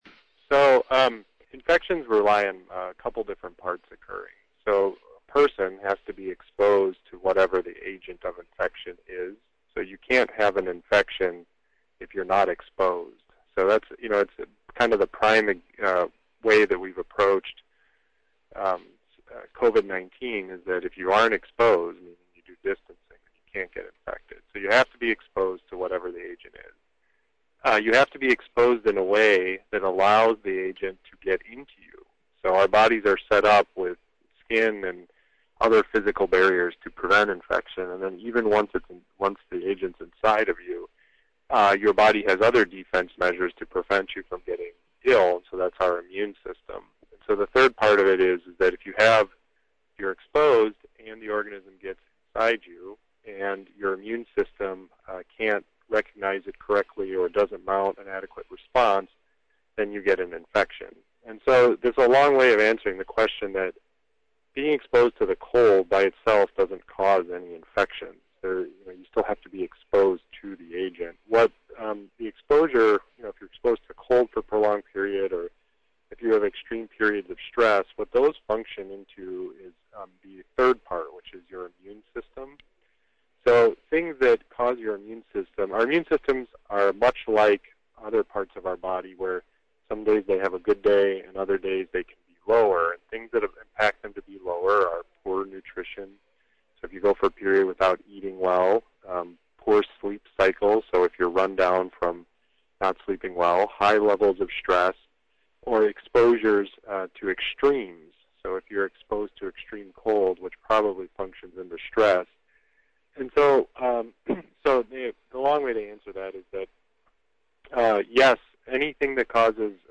Here’s the full interview